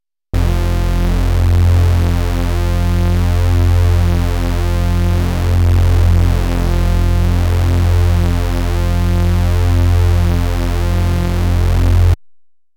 Two detuned saw oscillators each with independant PW modulation:-
2 x detuned saws PW-modulated]
.... and that's without unison/unison-detuned, and in mono.